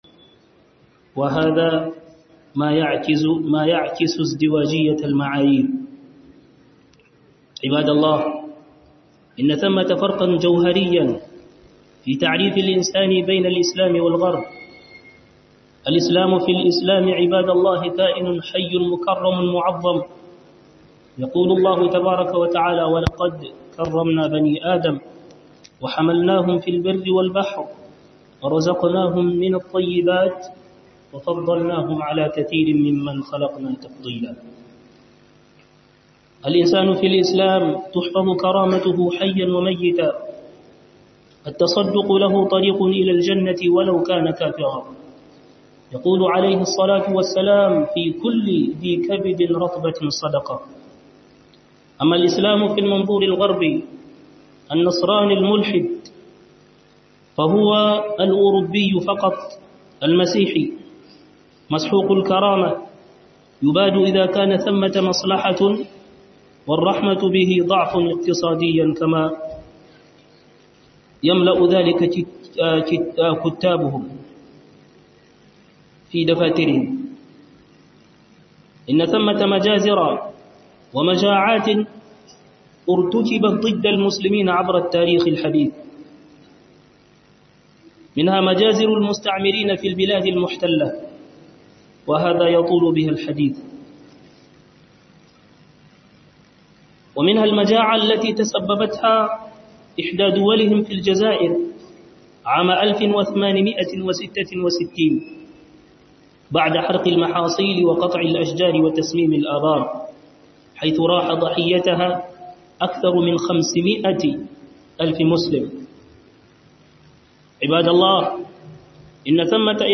Book Huduba